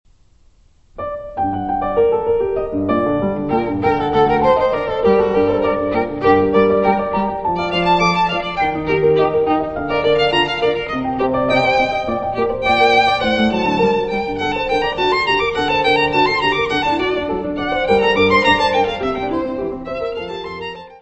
: stereo; 12 cm + folheto
Music Category/Genre:  Classical Music